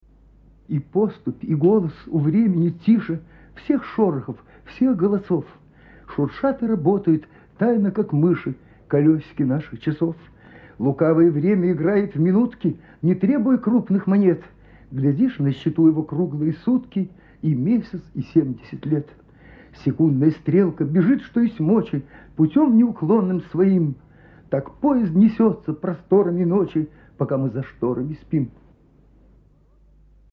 -i-postup,i-golos-marshak.(avtor)mp3.mp3